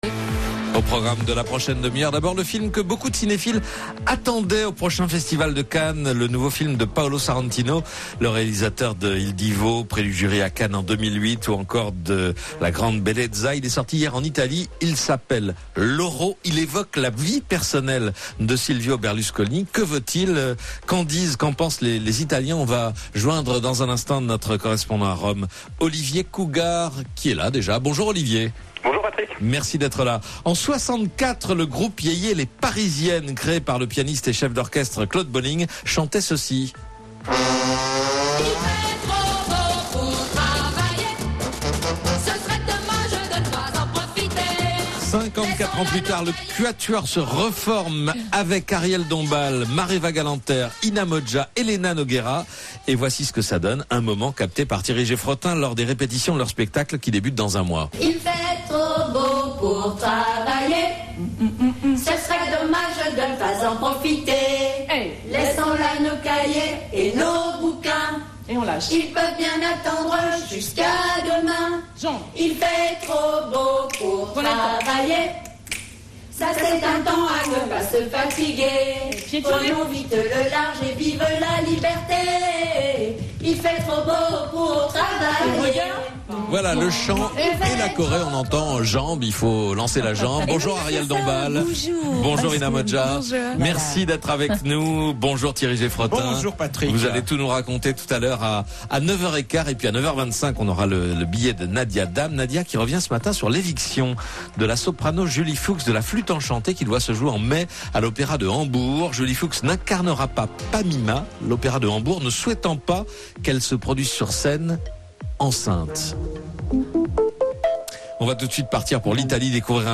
Les Parisiennes en interview dans Laissez-vous tenter sur RTL